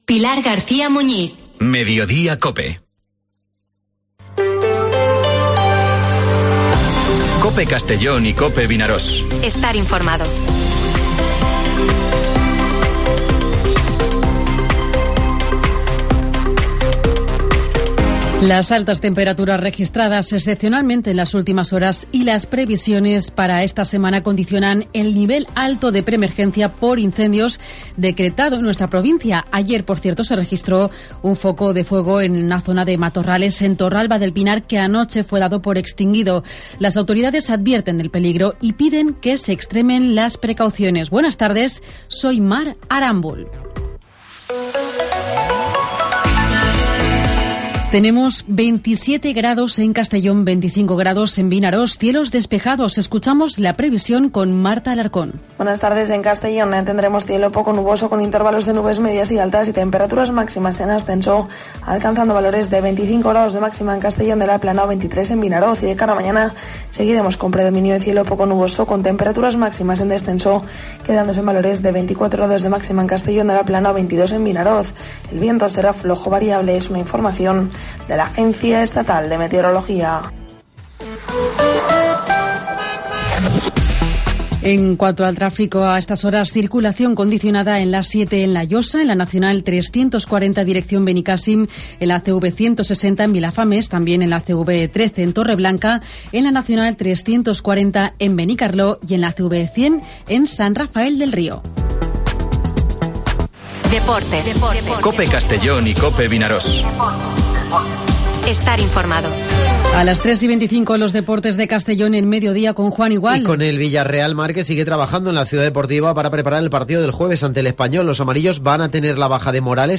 Informativo Mediodía COPE en Castellón (25/04/2023)